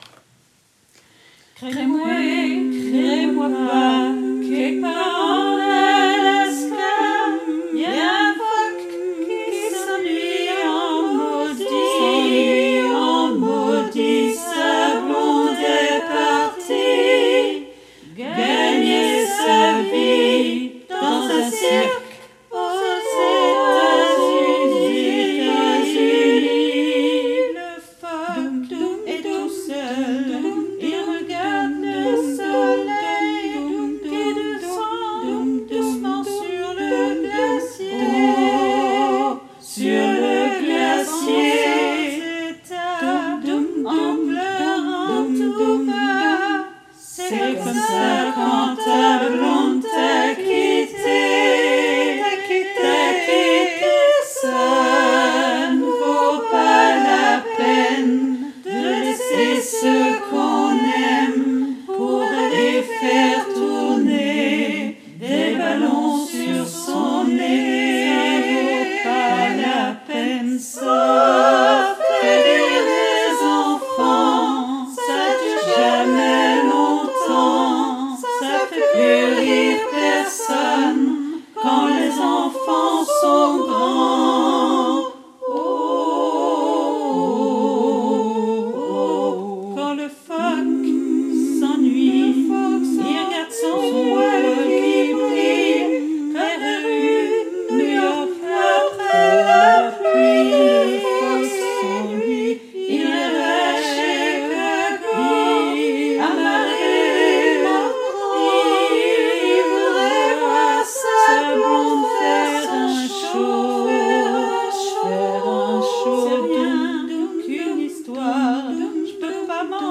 MP3 versions chantées
Tutti : SATB